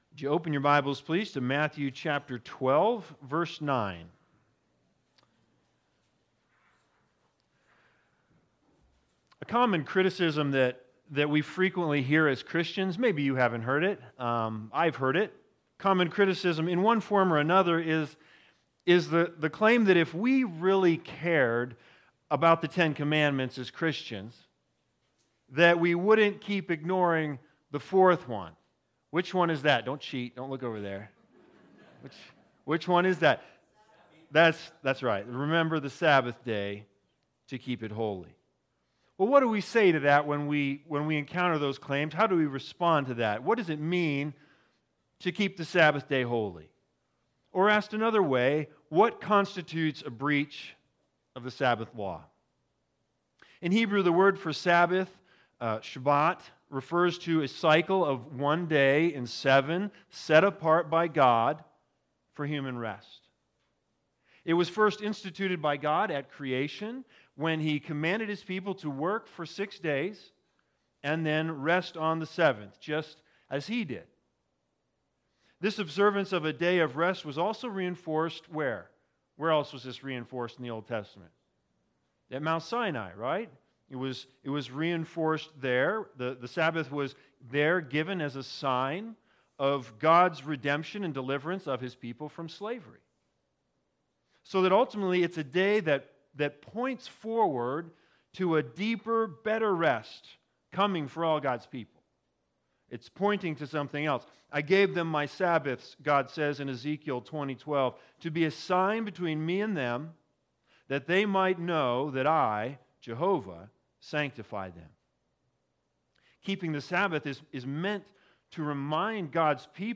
Matthew 12:9-14 Service Type: Sunday Sermons The Big Idea